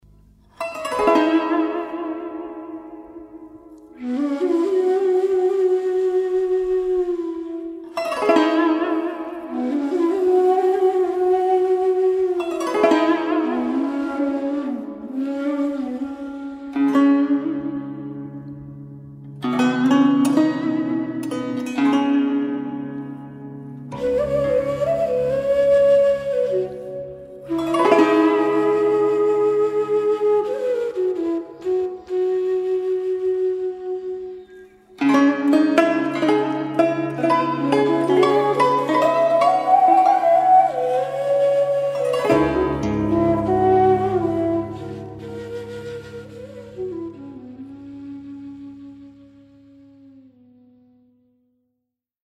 Avoharp, Atem- und Stimm-Perkussion, Griechische Doppelflöte
Trompete
instrumentale Urfassung